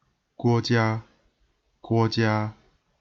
Mandarin pronunciation